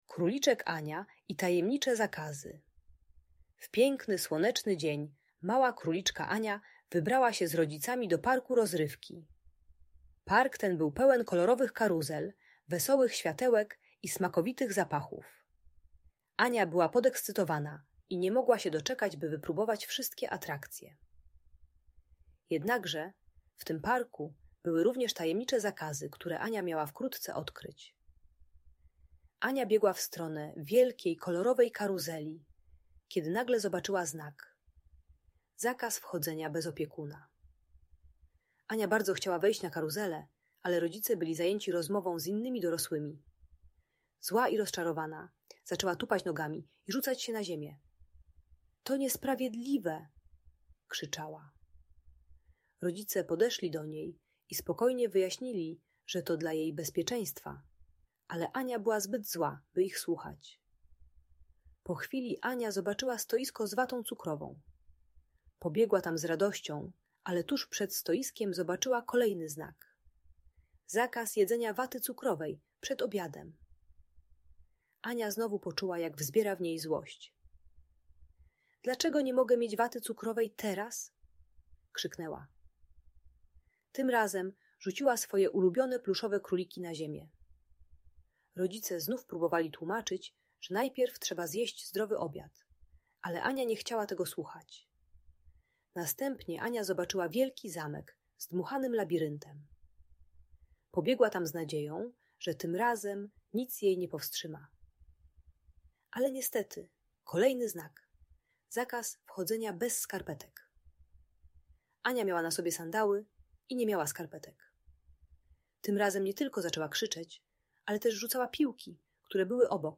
Króliczek Ania i Tajemnicze Zakazy - Audiobajka